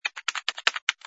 sfx_keyboard_flurry05.wav